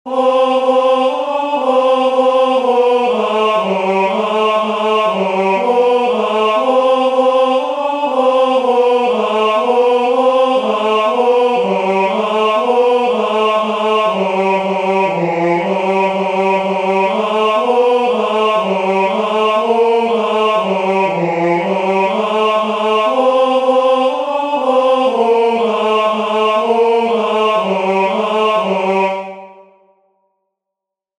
"Sancti per fidem," the second responsory verse from the first nocturn of Matins, Feast of St. Matthew, Apostle